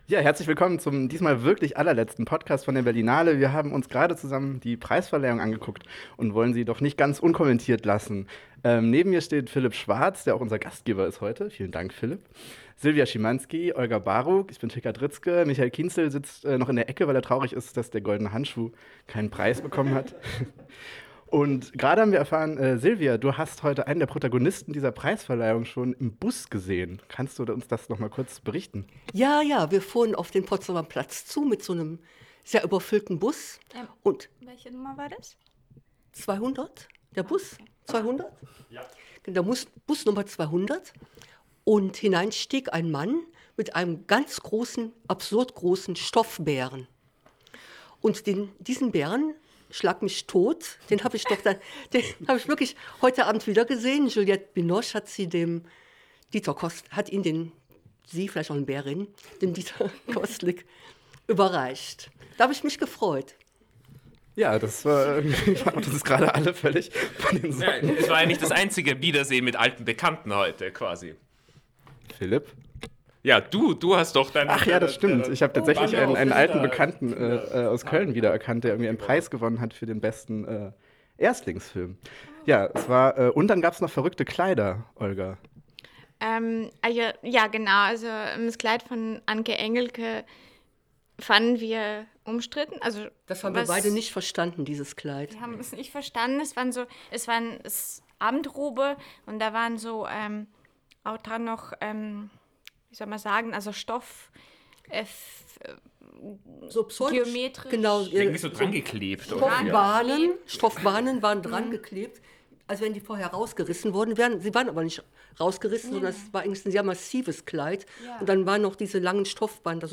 Die Filme im Gespräch zu hören ist so viel mehr als eine Kritik zu lesen.